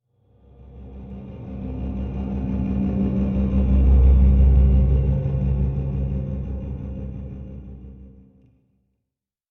ambient_drone_6.ogg